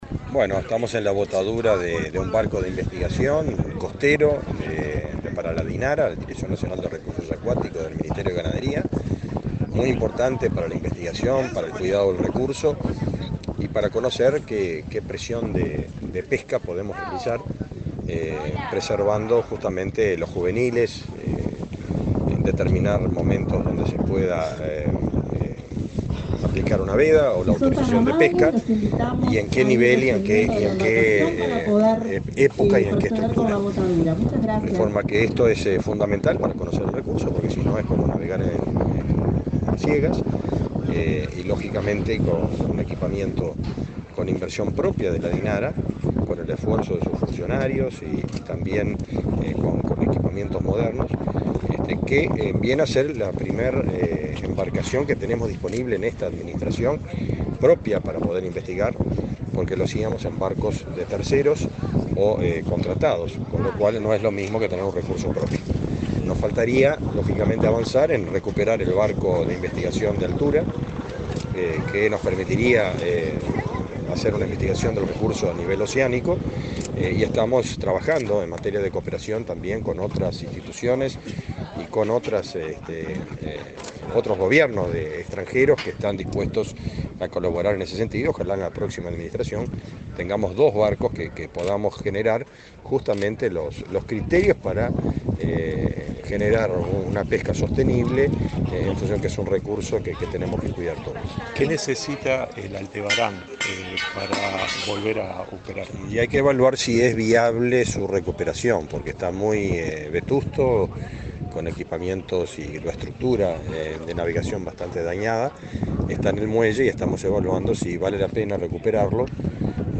Declaraciones del ministro de Ganadería, Fernando Mattos
El ministro de Ganadería, Fernando Mattos, dialogó con la prensa, luego del acto, realizado este martes 29 en el barrio Cerro, de Montevideo.